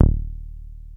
303 D#1 3.wav